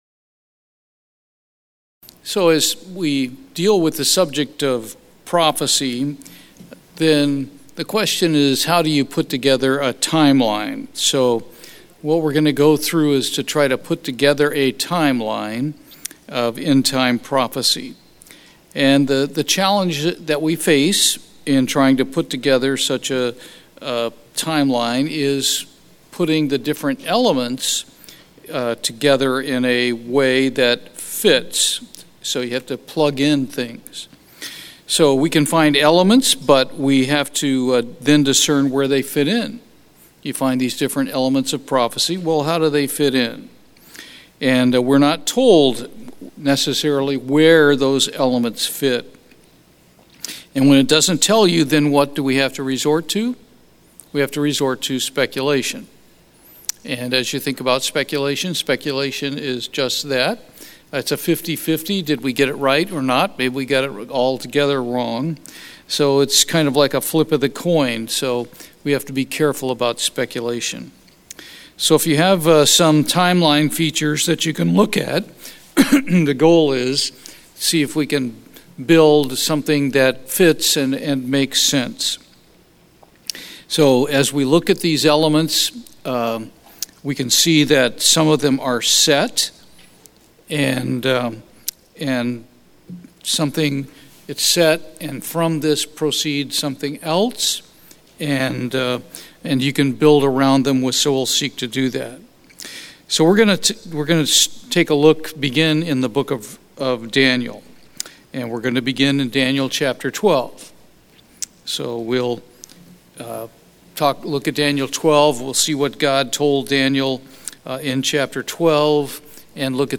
Bible study, Timeline of Prophecy